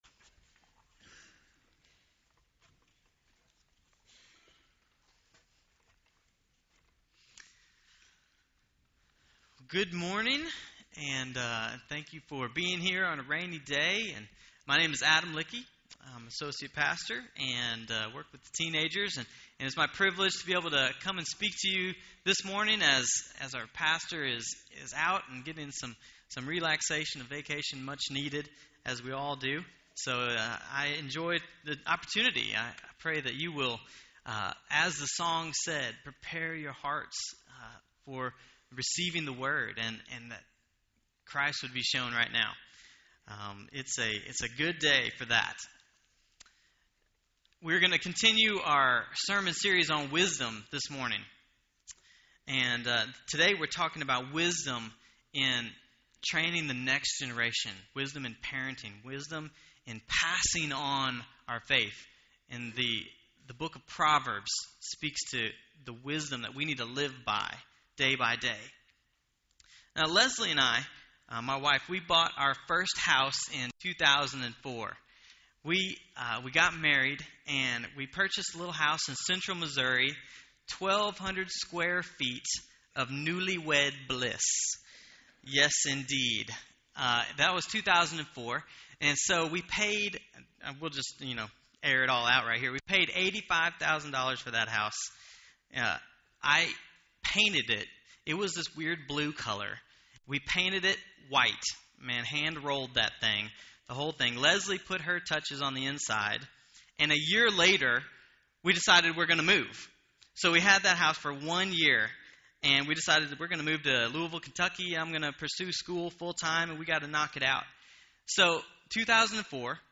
Are we training the next generation in the truth and passing on our faith? I was able to preach on just this issue to our congregation.